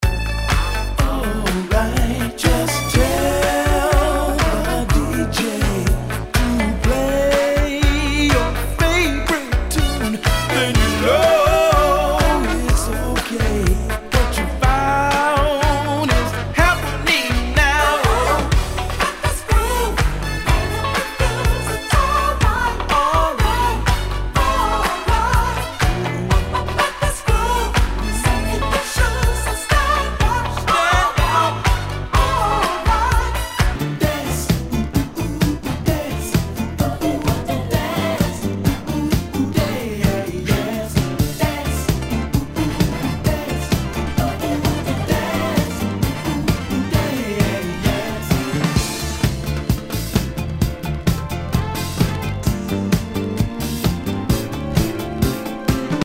SOUL/FUNK/DISCO
ナイス！ダンス・クラシック！
盤に傷あり全体にチリノイズが入ります